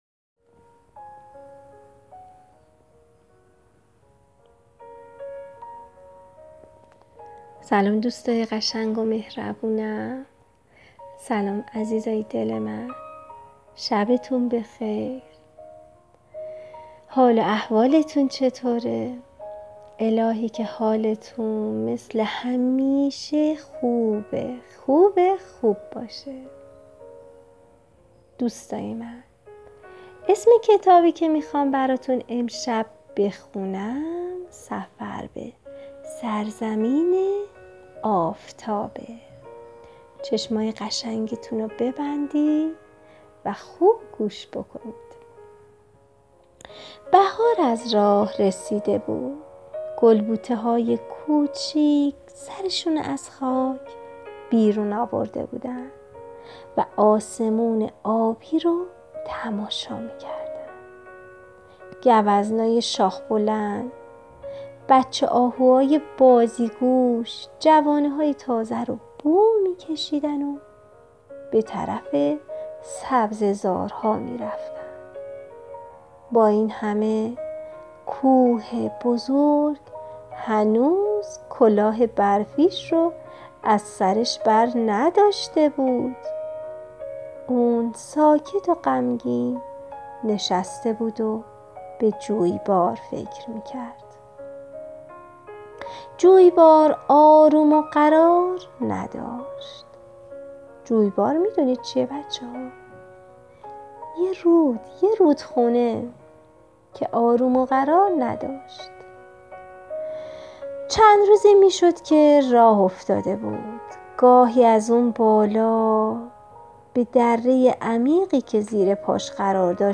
قصه صوتی کودکانه